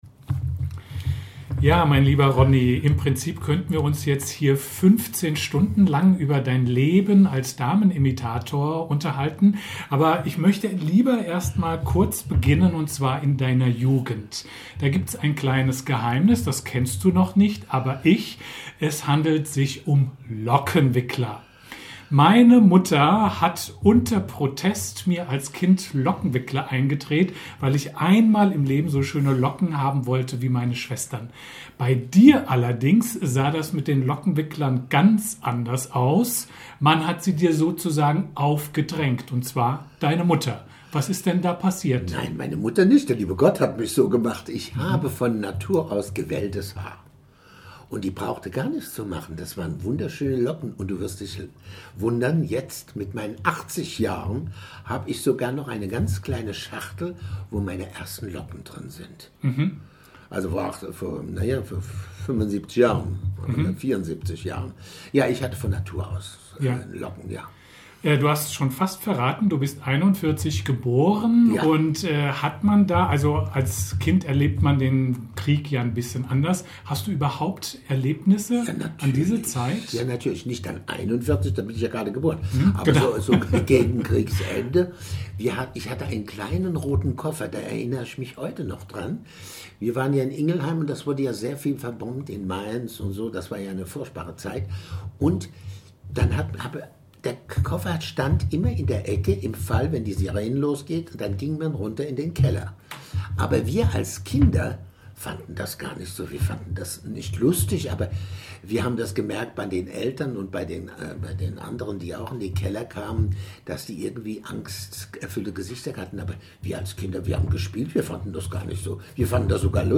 Teil 1 des Interviews